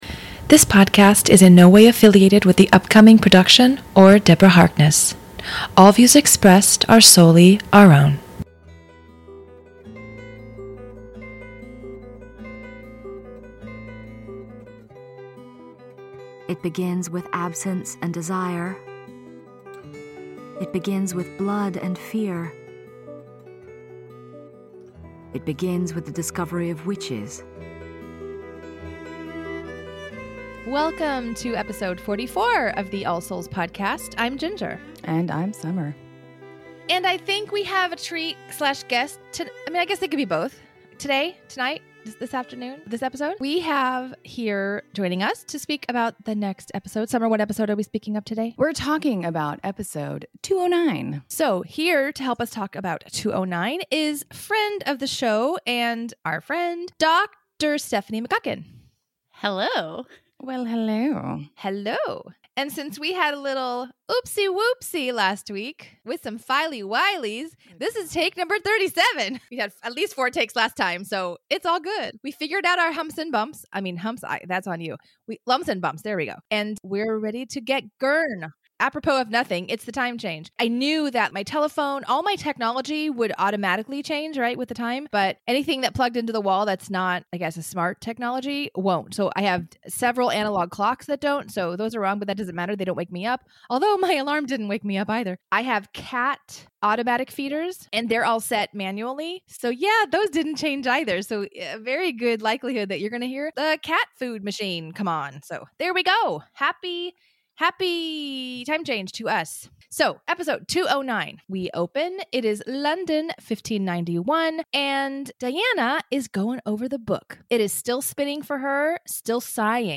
All clips from the A Discovery of Witches audiobook are courtesy of and copyright Recorded Books and Penguin Random House Audio .